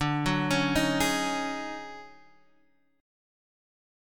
DM7-5-9 chord {x 5 4 6 4 4} chord